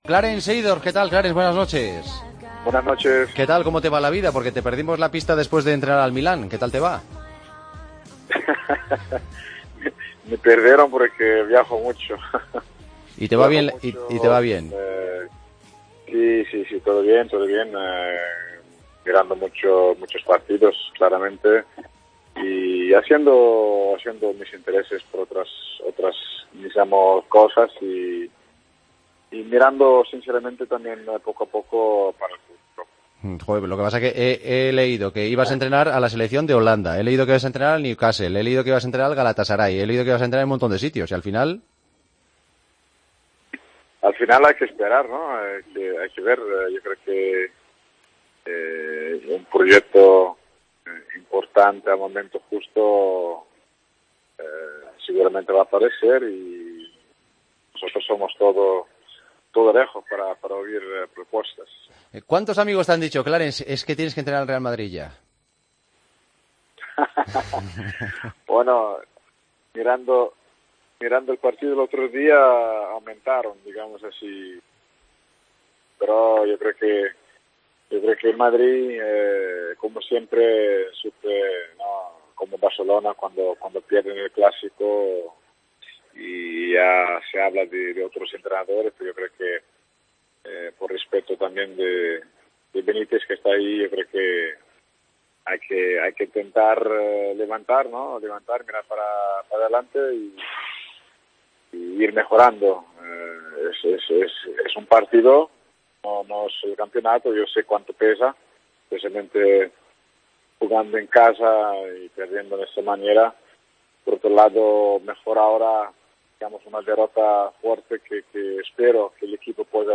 AUDIO: Clarence Seedorf, ex jugador del Real Madrid, ha pasado por los micrófonos de El Partido de las 12 , y ha analizado la actualidad del equipo...